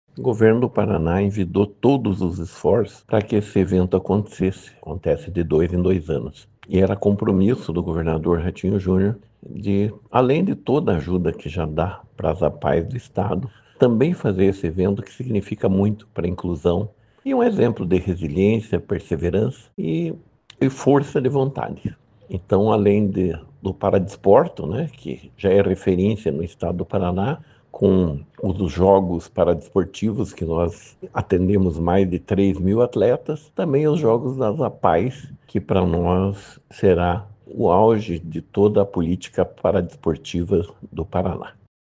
Sonora do secretário de Esporte, Hélio Wirbiski, sobre as Olimpíadas Especiais das APAEs em Foz do Iguaçu